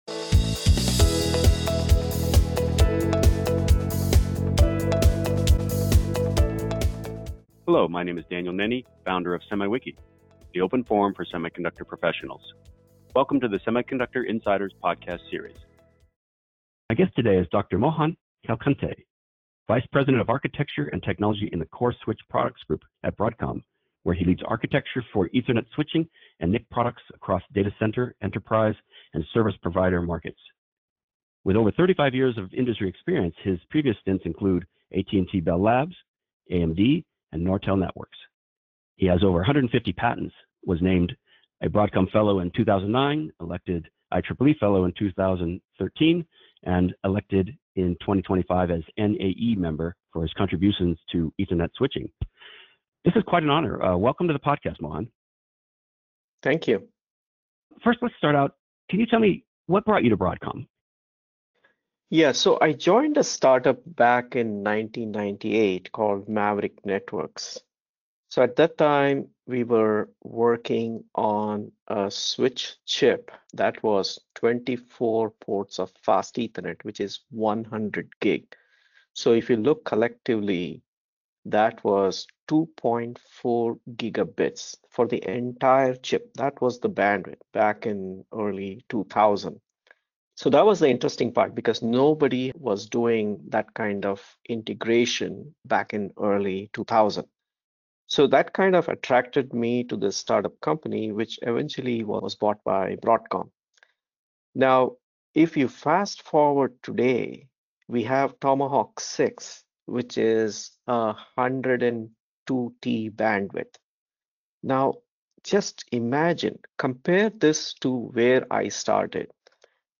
In this very informative discussion, items such as Ultra Ethernet, the Ultra Ethernet Consortium (UEC) and other open standards are discussed. The requirements for scale-up and scale-out are discussed.